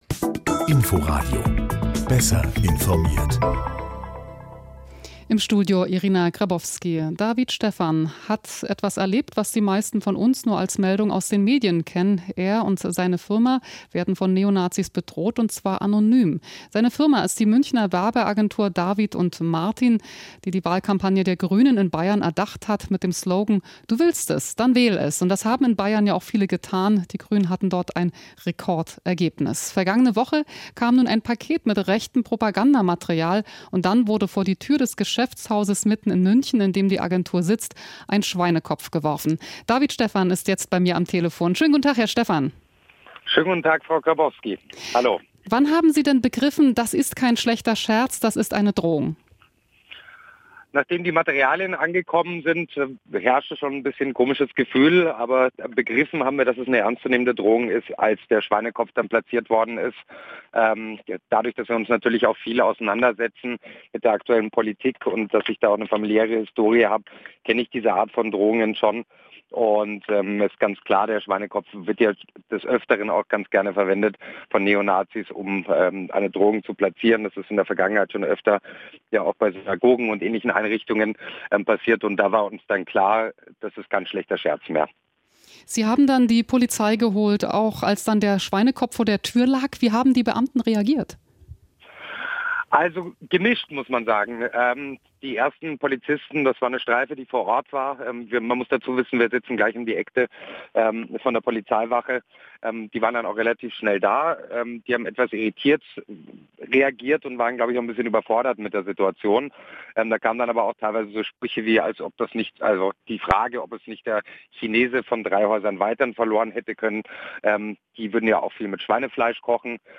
interview_agentur.mp3